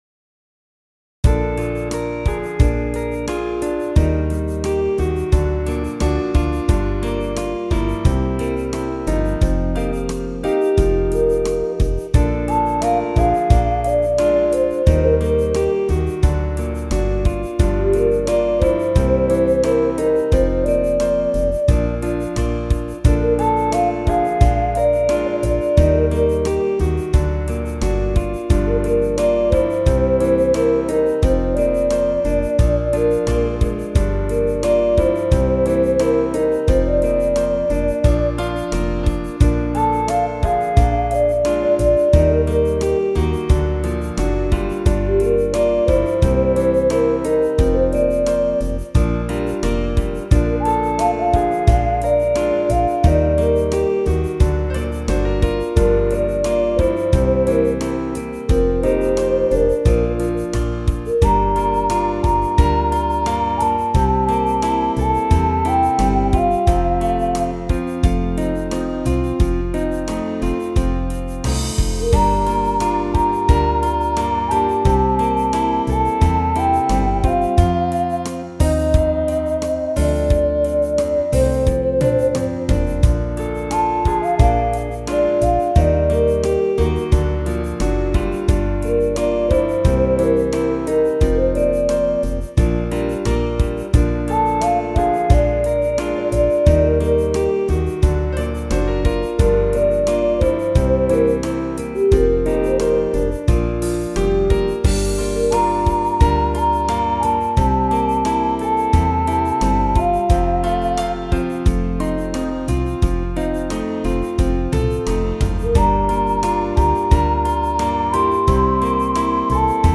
The midi sequence is on the Pop/Rock and Jazz/Swing lists.